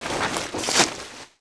WAV · 61 KB · 單聲道 (1ch)